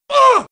Player_Death 01.wav